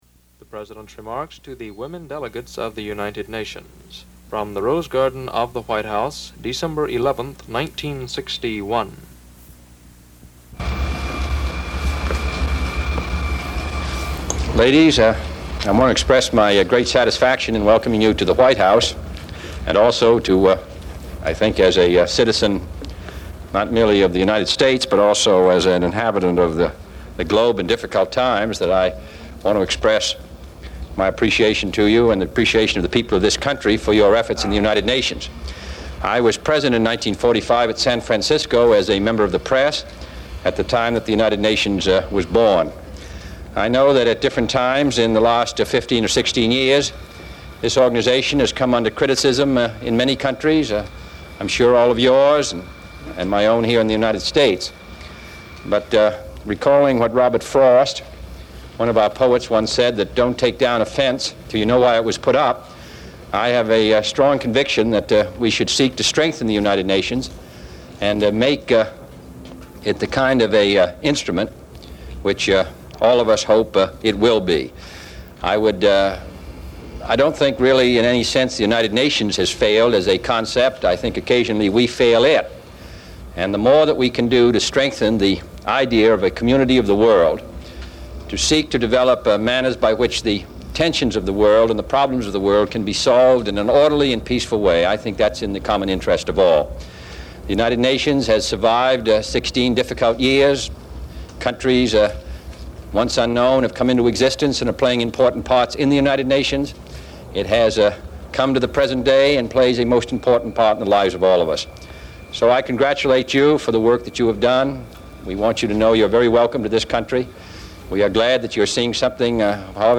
Presidential Speeches
President Kennedy meets with a delegation of female representatives from the United Nations at the White House. He mentions his commitment to the ideas that led to the United Nations' founding and speaks with hope for how it may be used to promote diplomacy in the future.